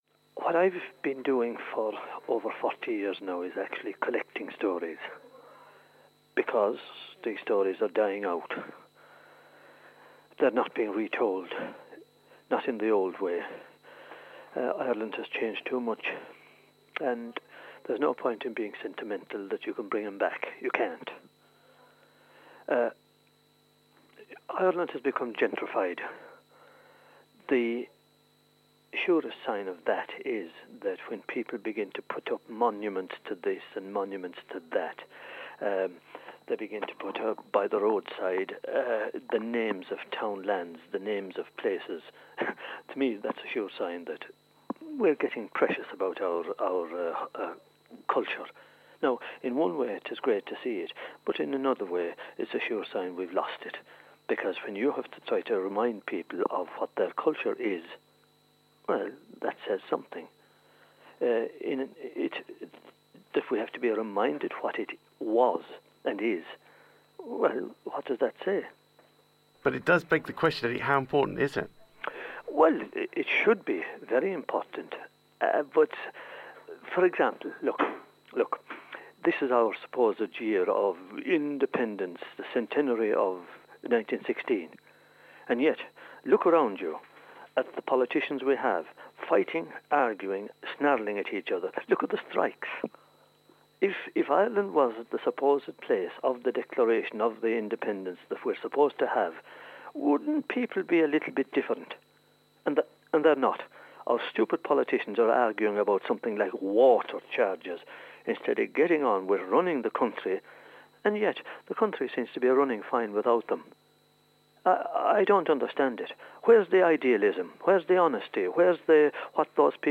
Seanchaí Eddie Lenihan talks about the importance of storytelling and preserving our oral heritage.